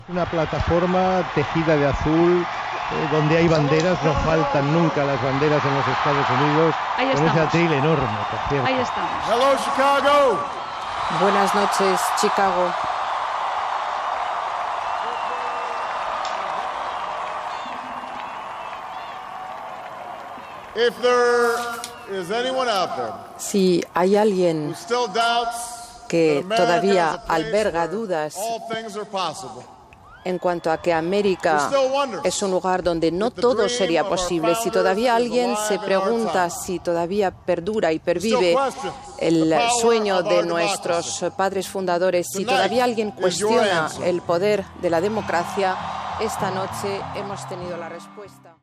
Hora, connexió amb Chicago, Barack Obama guanya les eleccions a la presidència dels EE.UU. i la CNN confirma que serà el nou president.
Discurs de Barack Obama a Chicago.
Informatiu